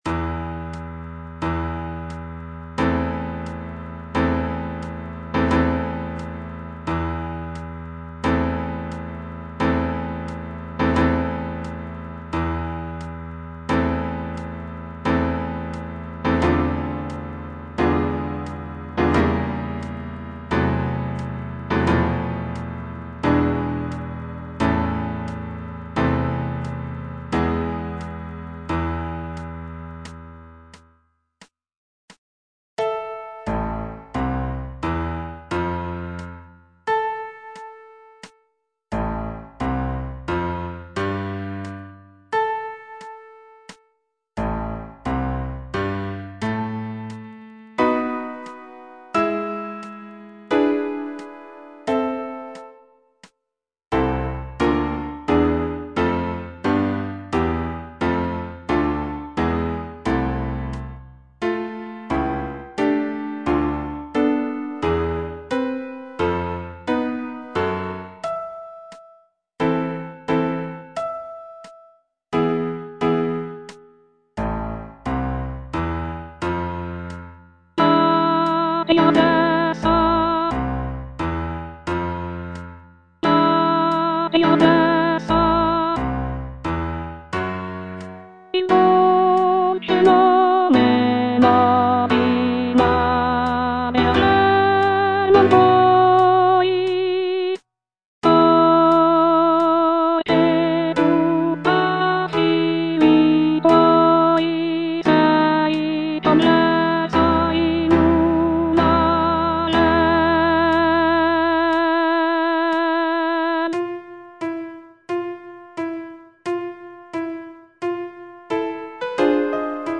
G. VERDI - CORO DI PROFUGHI SCOZZESI FROM "MACBETH" Soprano III (Voice with metronome) Ads stop: auto-stop
The piece features rich harmonies and powerful melodies that evoke a sense of sorrow and longing.